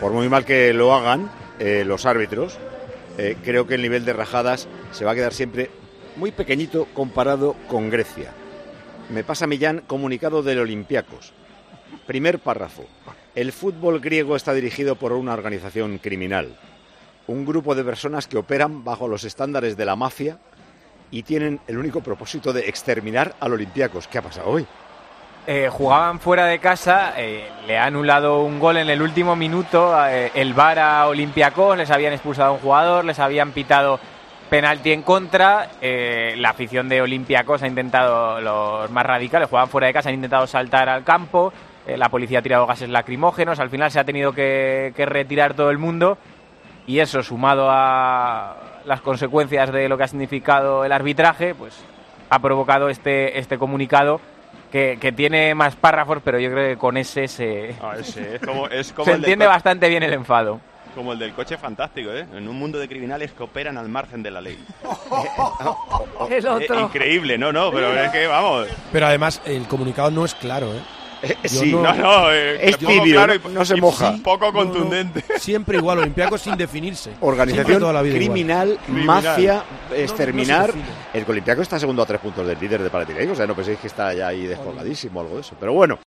En Tiempo de Juego, el director y presentador del programa líder de la radio deportiva del fin de semana, Paco González, se hizo eco de esta jugada y lo comentaba de la siguiente manera.